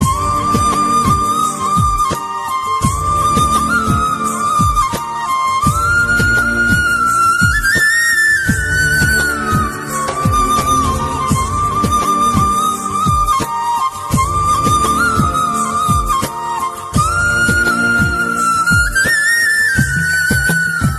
Bansuri Ringtones
Instrumental Ringtones
Peaceful Ringtones